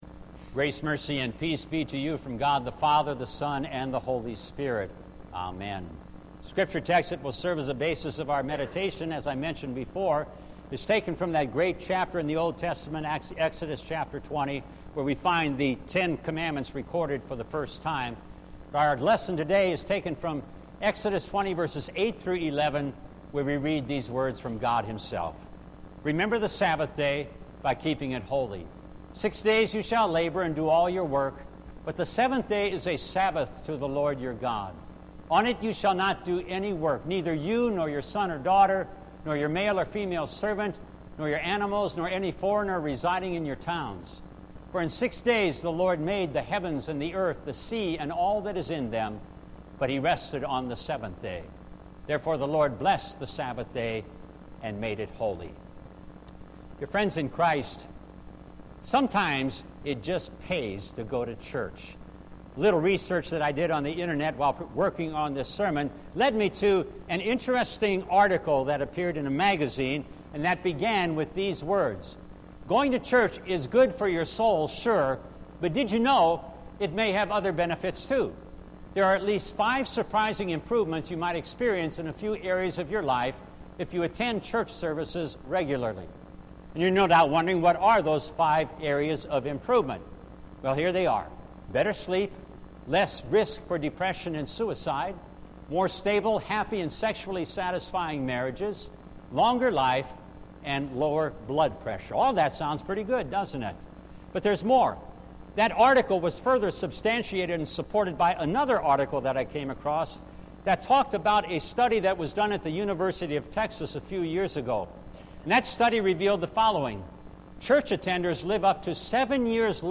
July-21-2019-Sermon.mp3